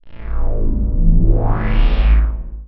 space_robot.flac